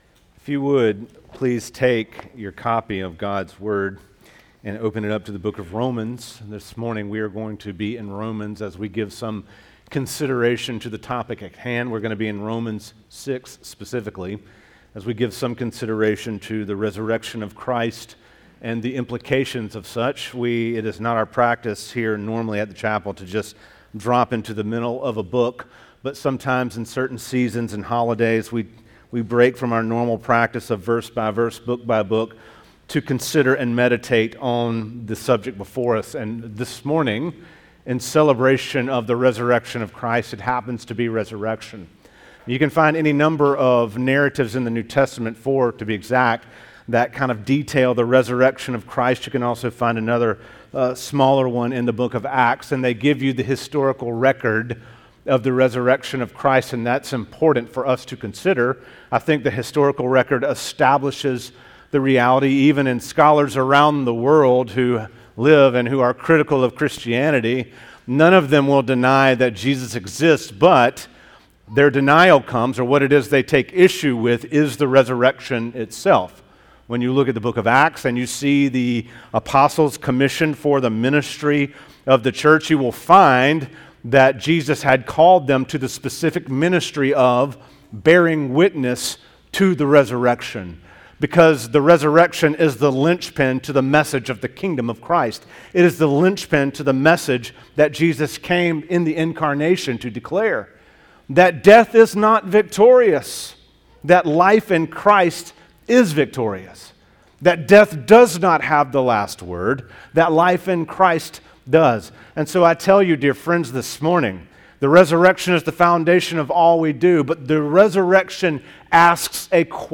Series: Easter Topic: Special Occasions